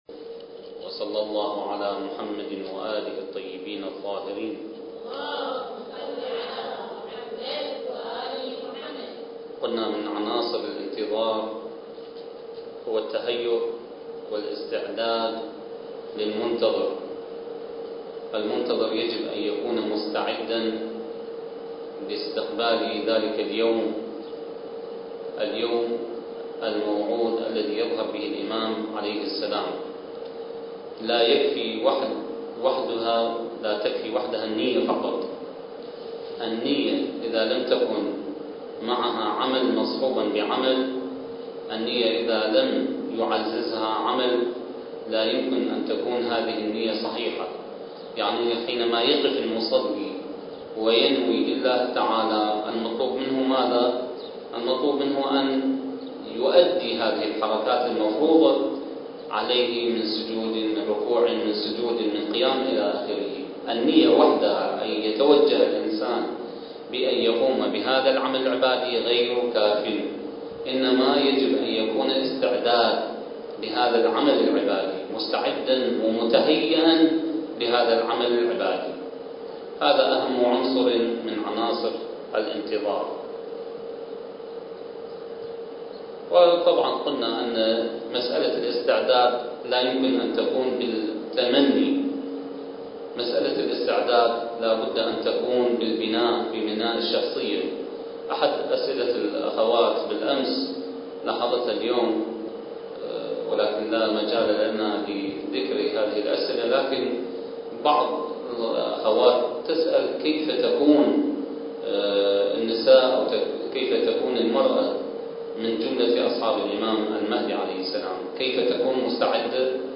سلسلة: المرأة والانتظار (3) الدورة الثقافية المهدوية للأخوات المؤمنات التي أقامها مركز الدراسات التخصصية في الإمام المهدي (عجّل الله فرجه) تحت شعار (بين صمود الانتظار وبشائر الظهور) التاريخ: 2006